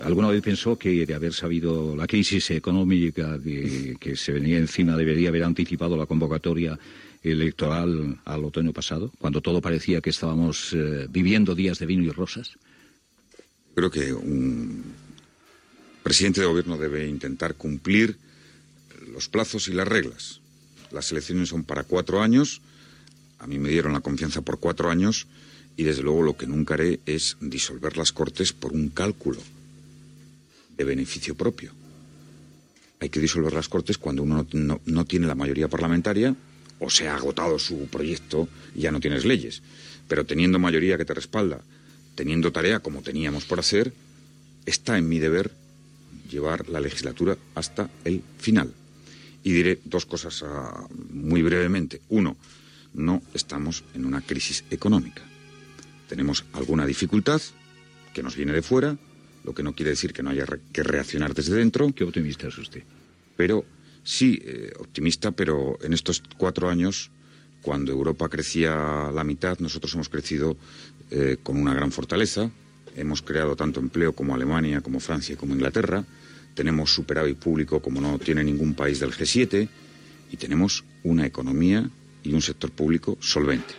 Fragment d'una entrevista al president del govern espanyol José Luis Rodríguez després de la crisi econòmica de l'any 2008.
Info-entreteniment
FM